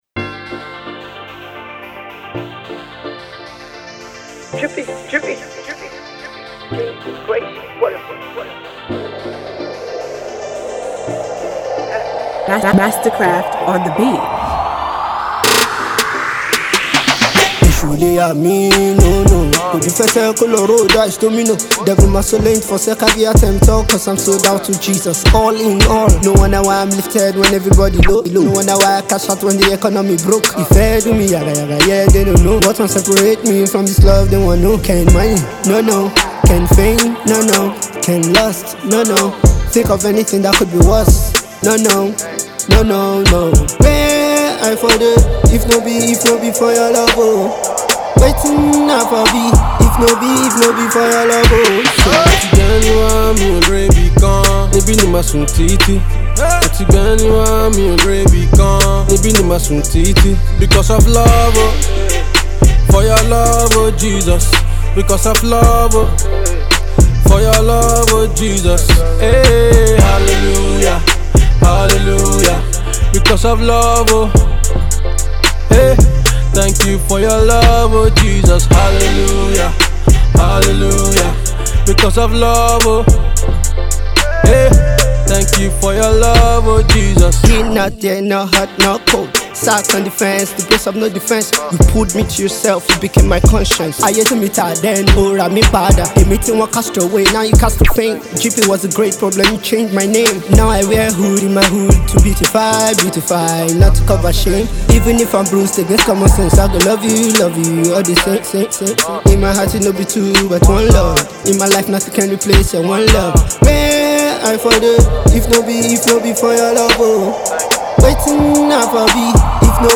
indigenous trap song